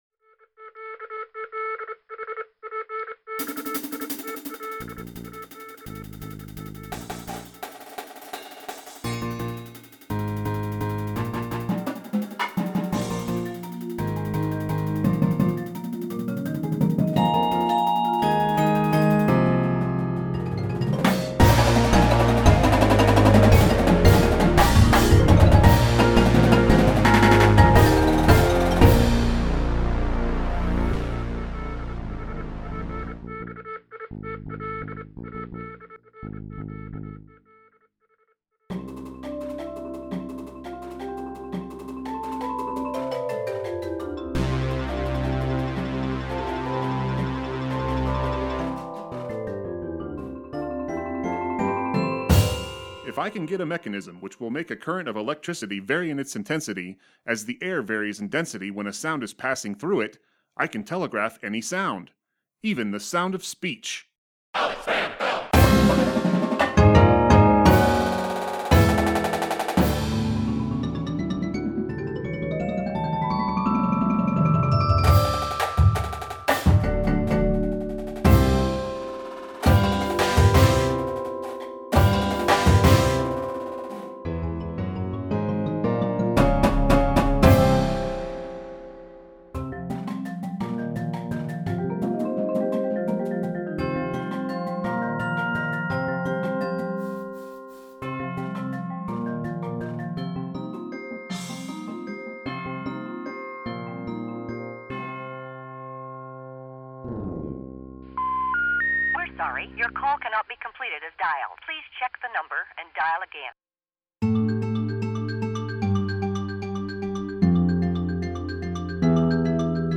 modern Indoor Percussion Show